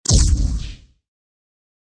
bomb.mp3